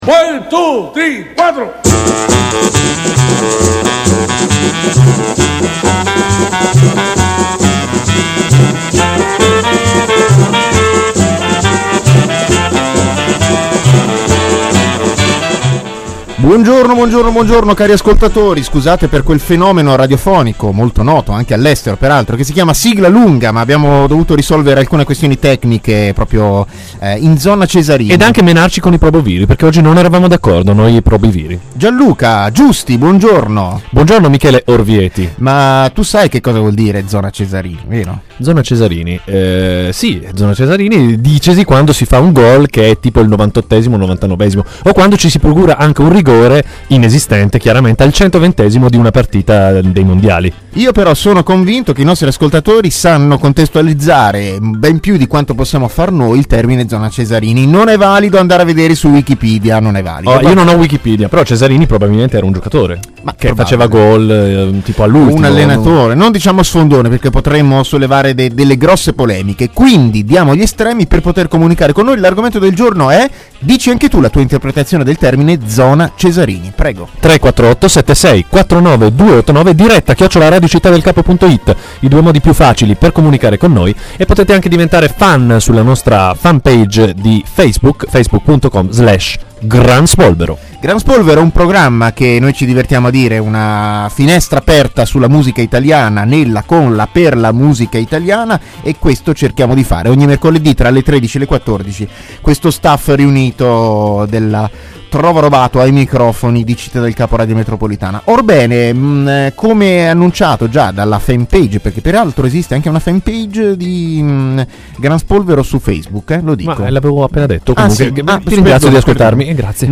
I'intervista radiofonica del 23/02/2011, in studio